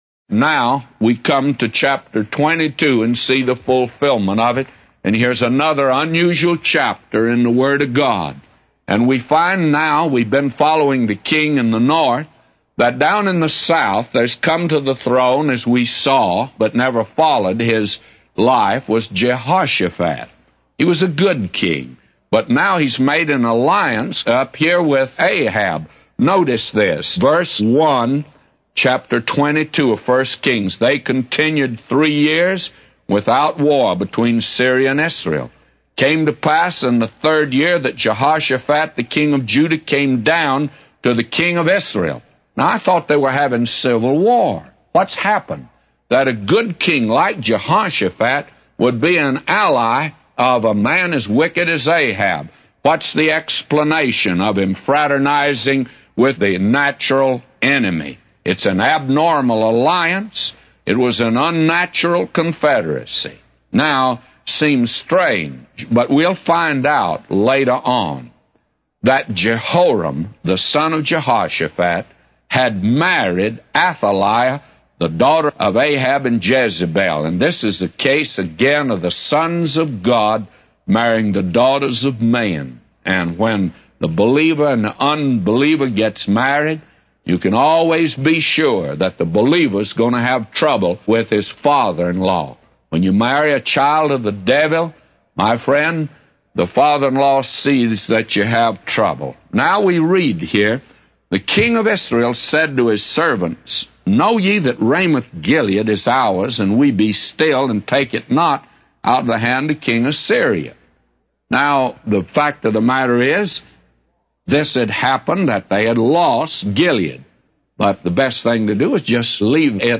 A Commentary By J Vernon MCgee For 1 Kings 22:1-999